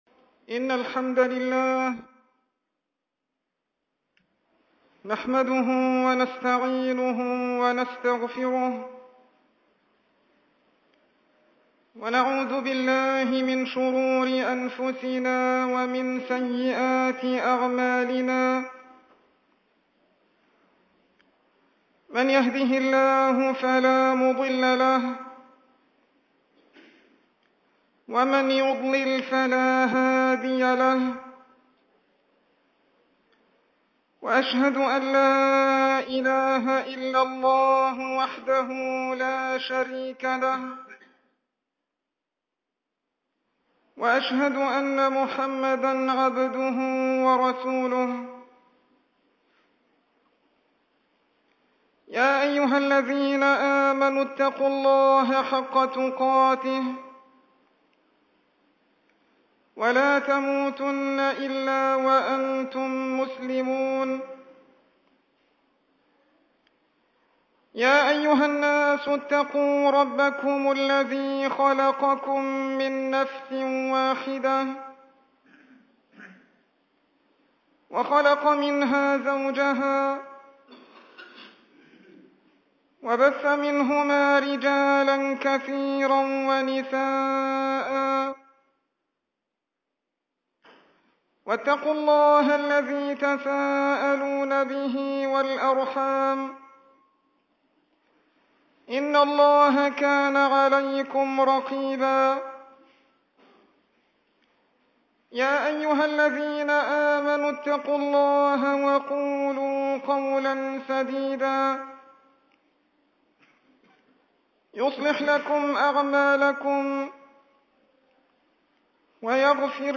الخطبة الثانية